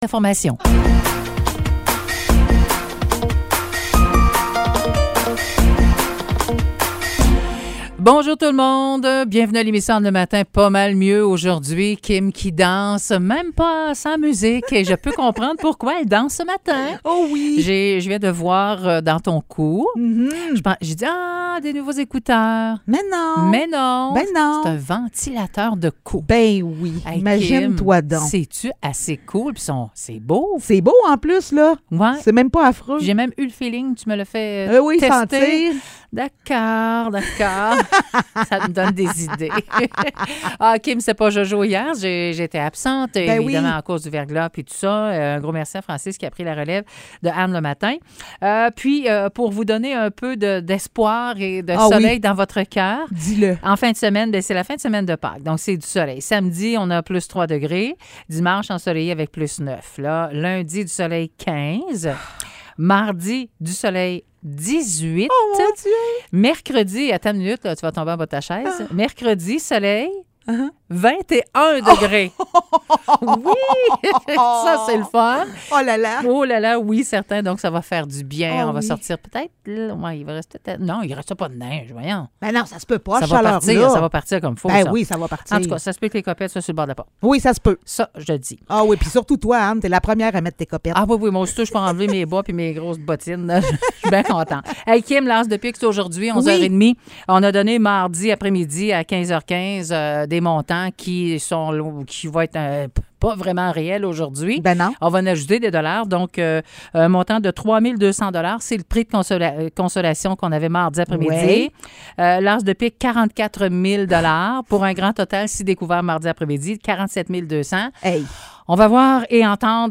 Nouvelles locales - 6 avril 2023 - 9 h